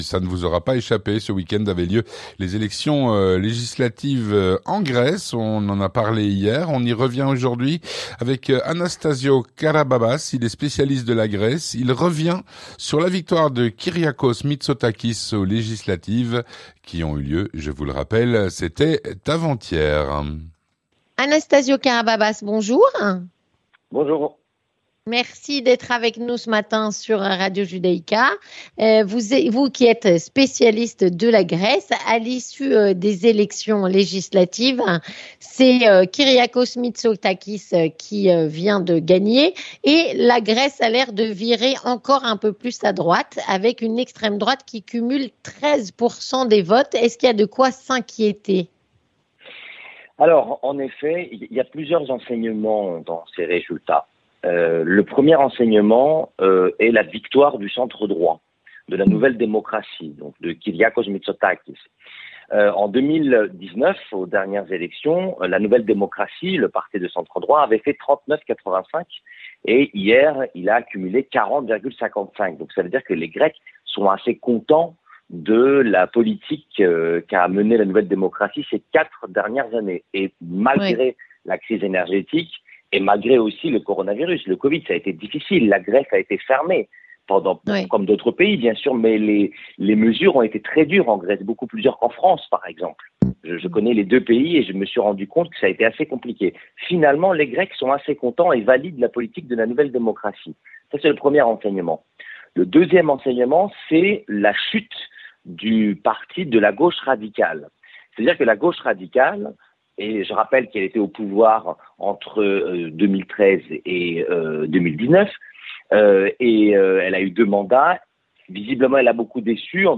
Entretien du 18H - La montée de l'extrême-droite aux dernières législatives